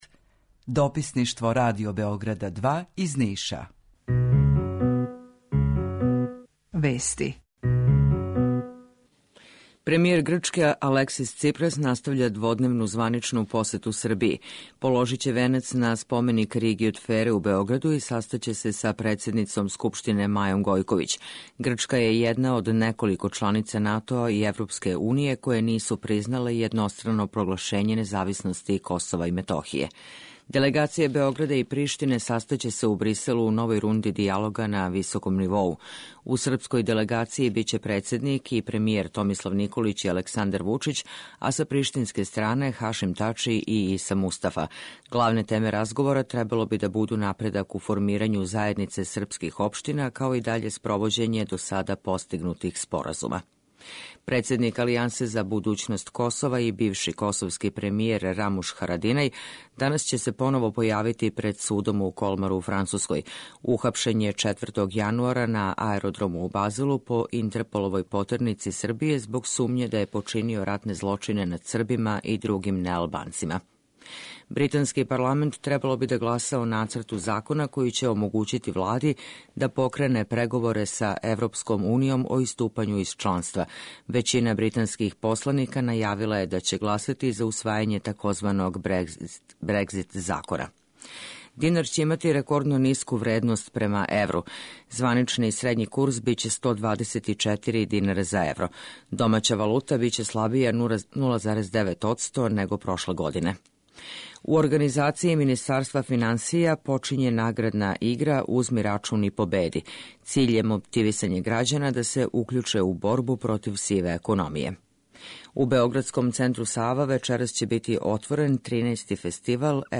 Jутарњи програм заједнички реализују Радио Београд 2, Радио Нови Сад и дописништво Радио Београда из Ниша.
У два сата, ту је и добра музика, другачија у односу на остале радио-станице.